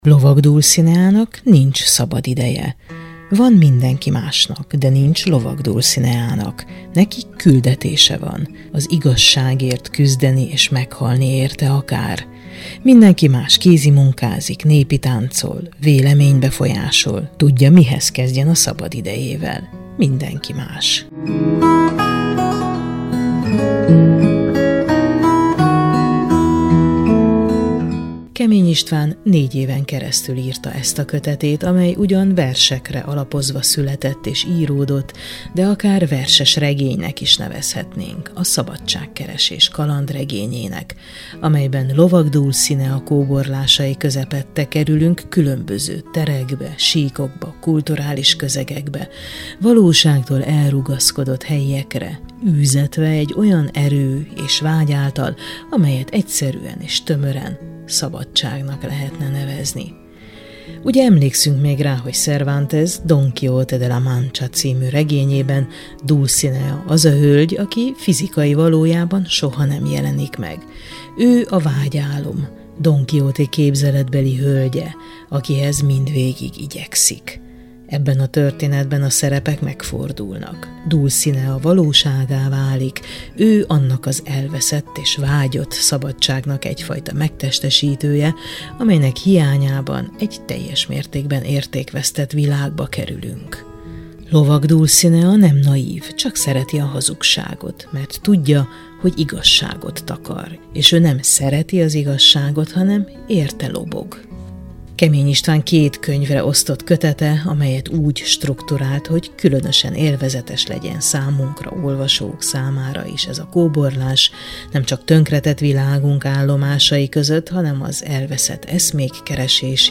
Enciklopédia a világ dolgairól – Kemény Istvánnal beszélgetünk Lovag Dulcineáról